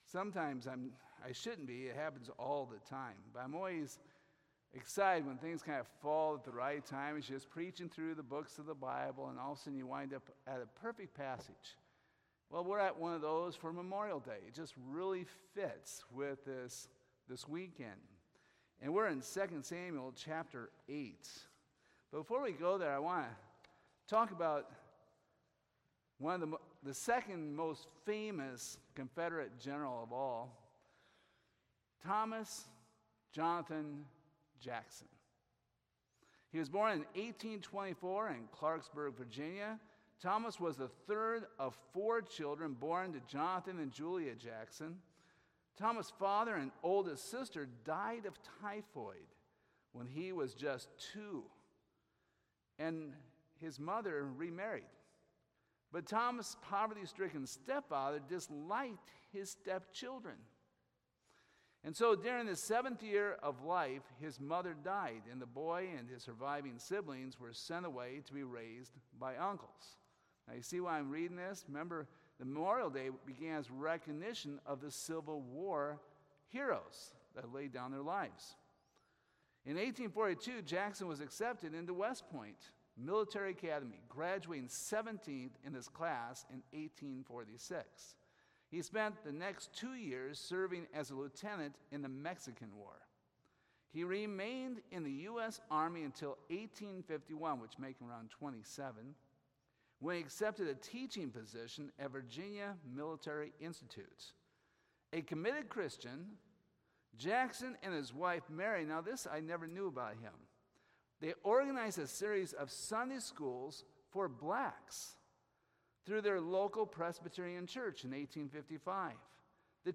2 Samuel 8 Service Type: Sunday Evening God grants King David success as he extends his kingdom.